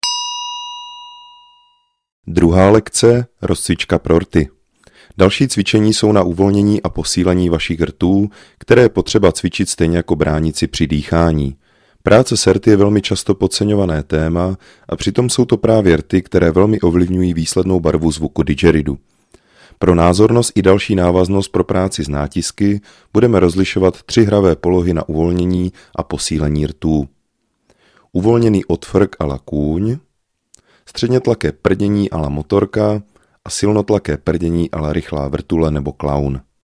VÝUKA HRY NA DIDGERIDOO I.
Didgeridoo je nástroj, jehož hluboký tón dokáže ukotvit pozornost v přítomném okamžiku.
Track 06 - 2 lekce - Rozcvicka pro rty.mp3